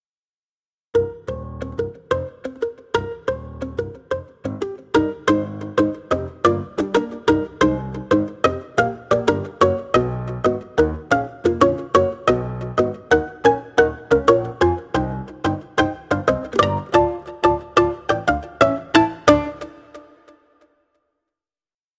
Dark Intervals Mandolin Textures 是一款使用纯声学曼陀林声音制作的乐器。
这款乐器有15个nki文件，其中两个是标准的闷音曼陀林声音，非常自然和实用。
其他的文件是各种纹理、垫音和氛围声音。有些声音经过了大量的处理，所以听起来和你期待的可能很不一样。
曼陀林是用Royer SF 12立体声麦克风和原装Neve 1073立体声前置放大器或偶尔用Millenia HV-3C来采样的。